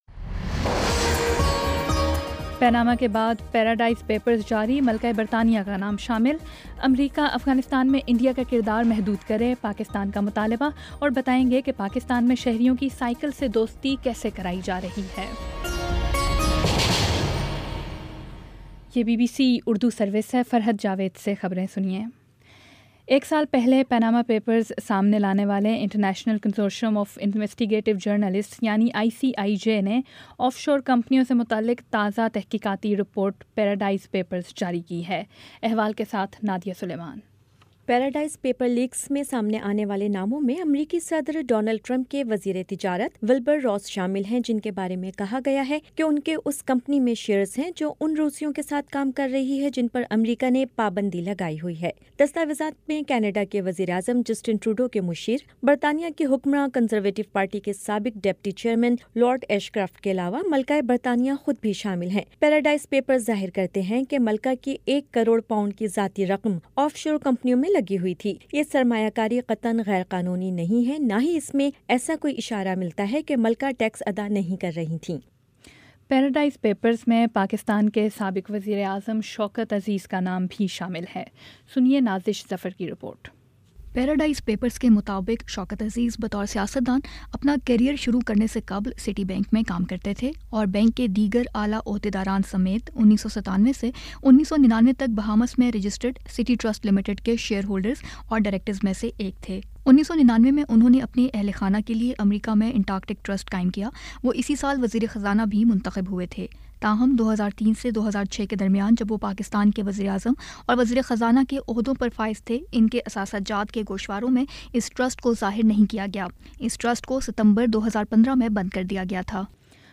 نومبر 06 : شام چھ بجے کا نیوز بُلیٹن